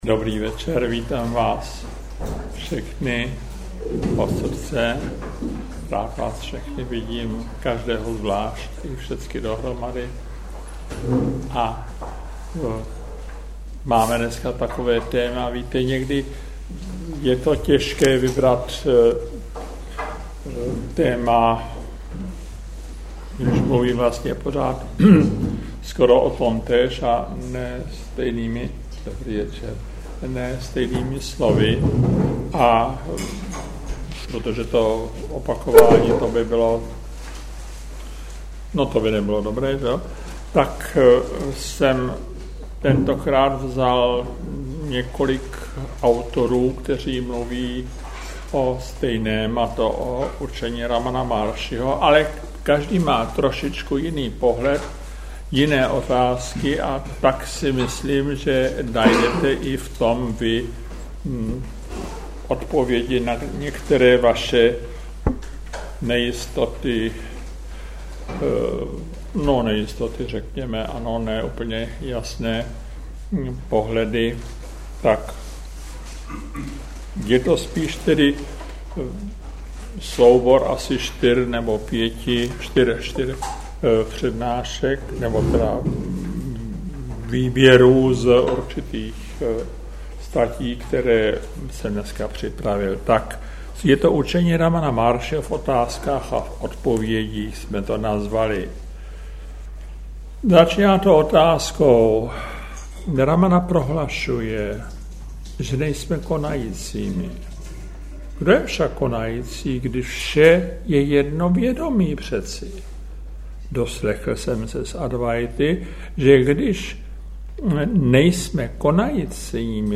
záznamy přednášek
Veřejné přednášky - Ramana Mahárši - život a učení